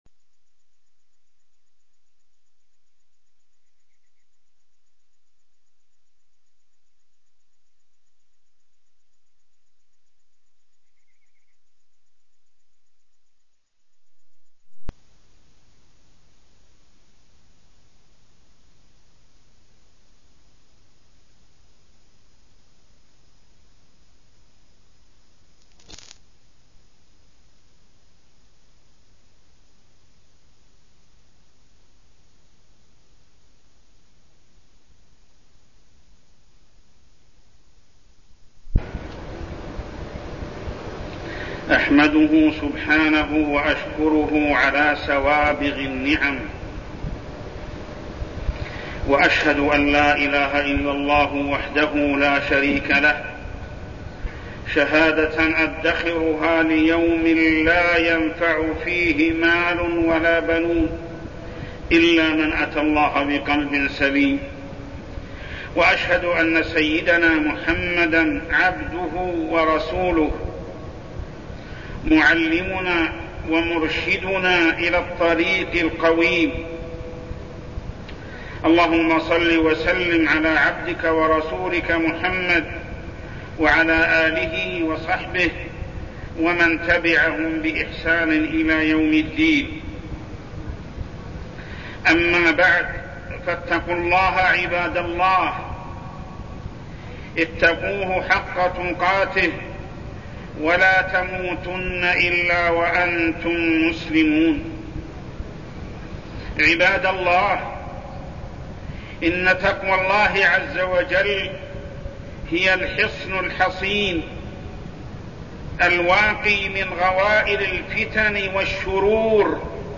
تاريخ النشر ١٩ ربيع الأول ١٤١٢ هـ المكان: المسجد الحرام الشيخ: محمد بن عبد الله السبيل محمد بن عبد الله السبيل أهمية العلم